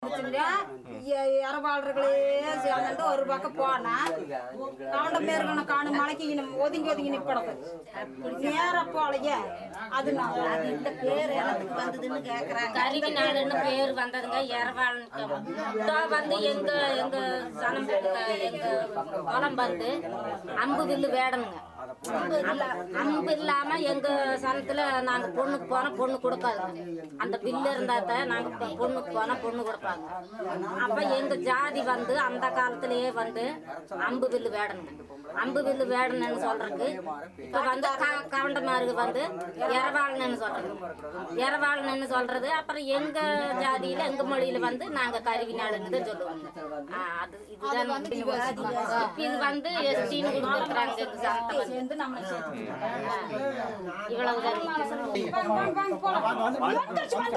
Conversation about the origin of the name Eravallan
Notes This is a conversation between the principal investigator and the consultants about the origin of the name of their language - Eravallan. The consultants use the name Karivinaal to refer to the language, however, outsiders as well the Census uses the name Eravallan to refer to their language.